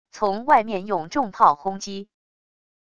从外面用重炮轰击wav音频